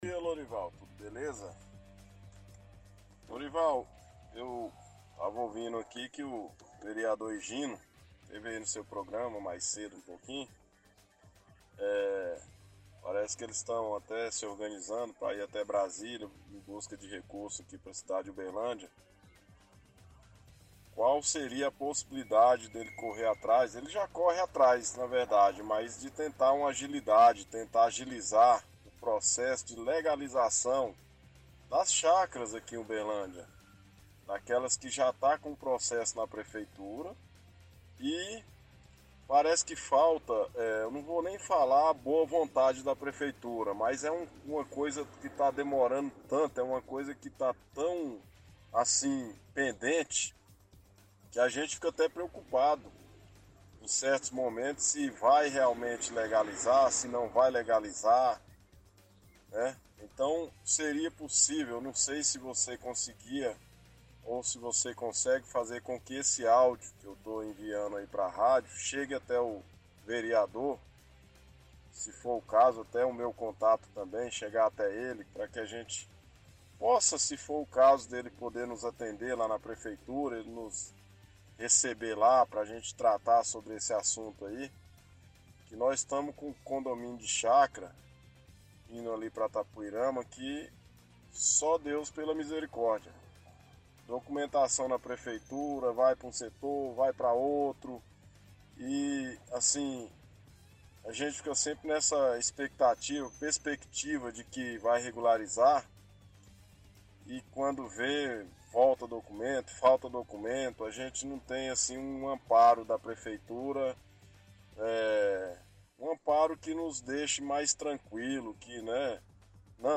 -Ouvinte pede ajuda do vereador Igino no processo de regularização de chácaras aqui na cidade. Diz que documentação já está na prefeitura e que estão na expectativa se vai ou não regularizar.